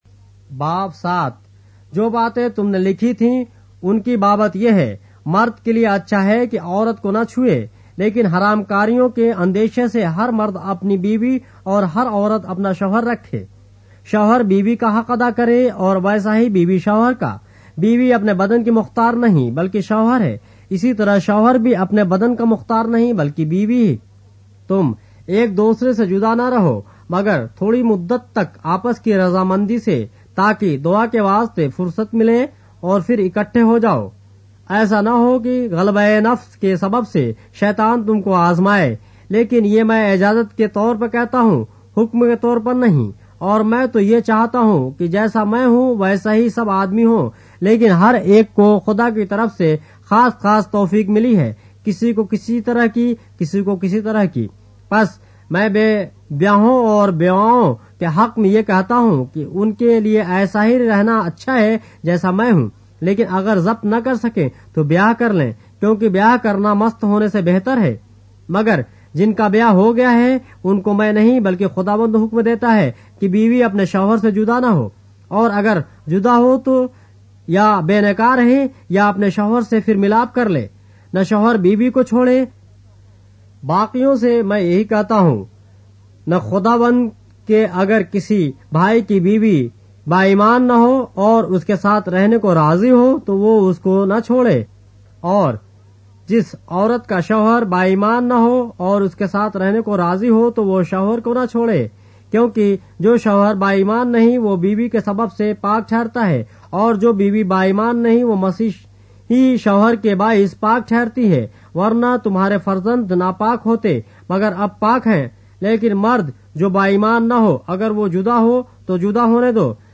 اردو بائبل کے باب - آڈیو روایت کے ساتھ - 1 Corinthians, chapter 7 of the Holy Bible in Urdu